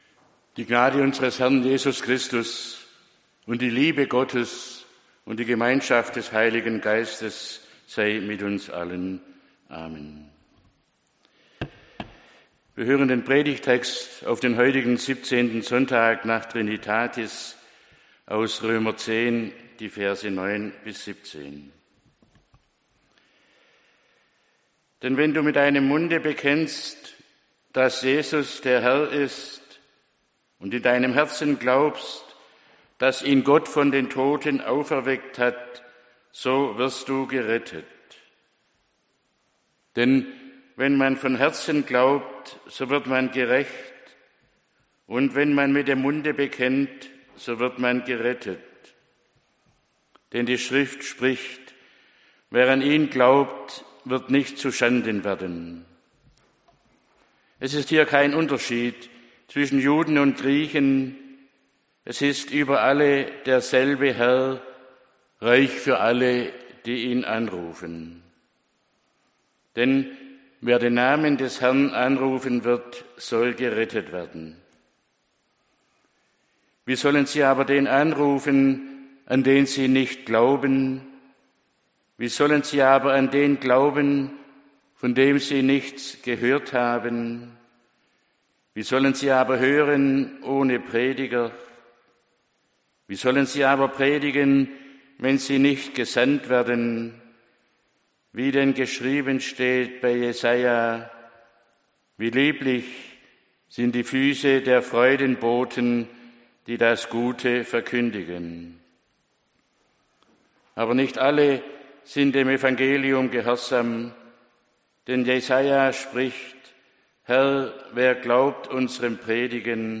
der-glaube-kommt-aus-der-predigt-roemer-10-9-17